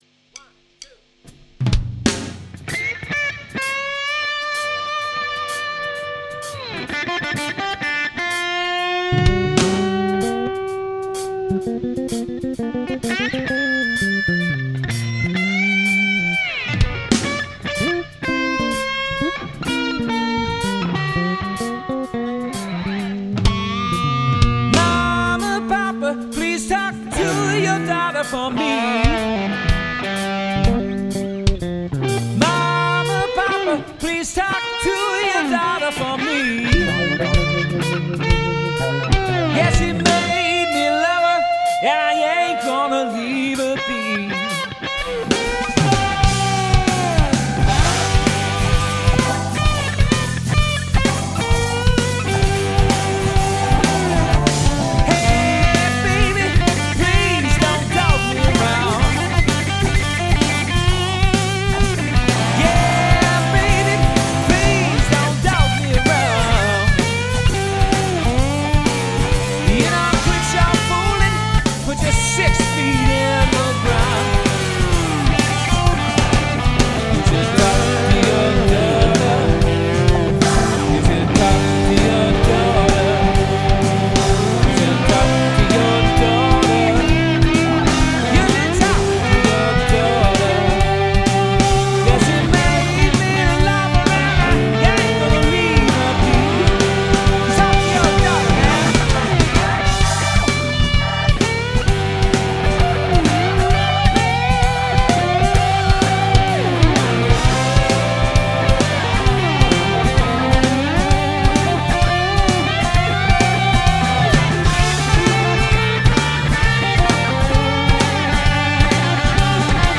Tausta menee G:stä ja löytyy täältä: